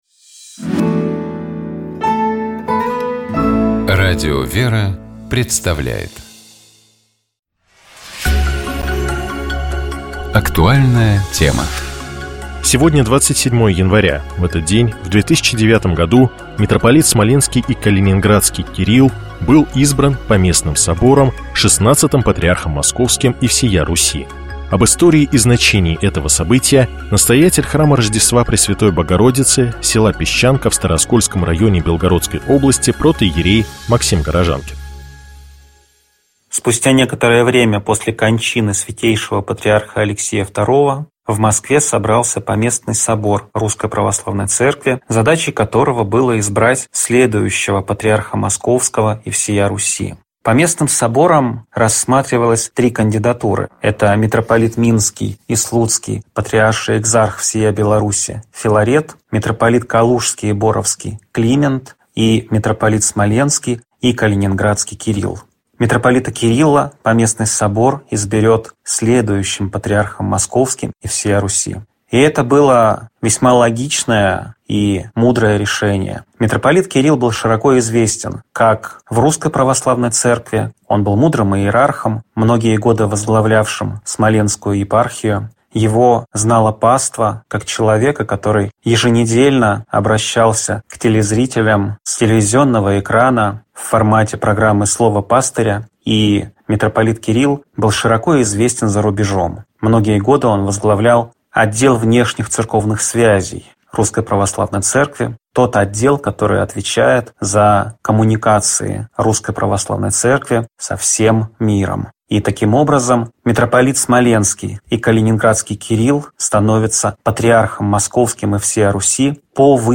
Здравствуйте, дорогие друзья.